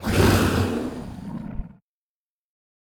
guardian_hit2.ogg